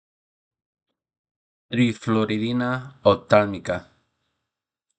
Pronunciar: